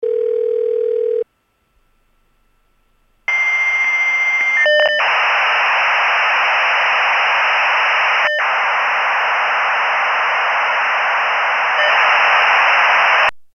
Modem Connect 2; 9, 600 Bps Modem Connect; Telephone Rings / Line Noise / Modem Connect. Computer.